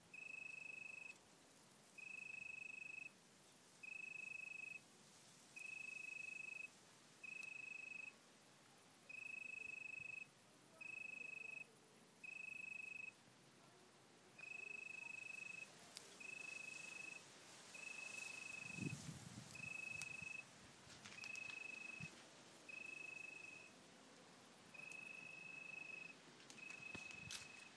Pretty little cricket